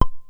detectorPing.wav